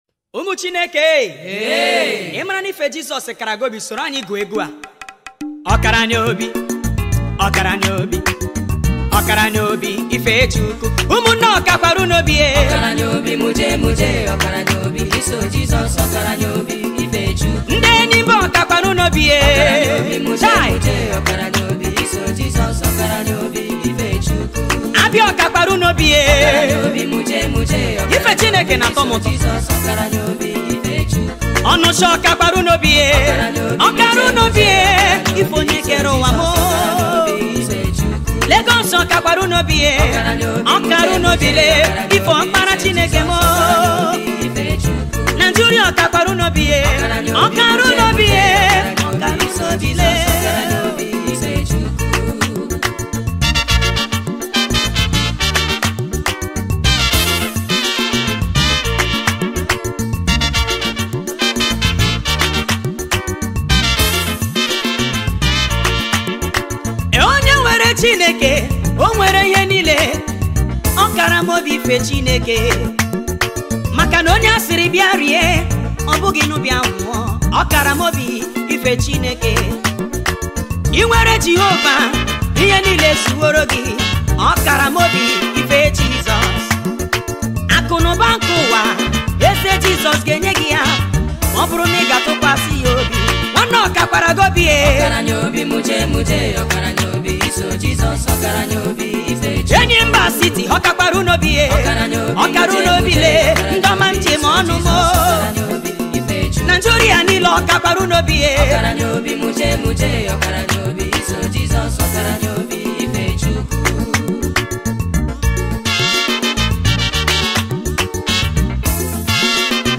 January 29, 2025 Publisher 01 Gospel 0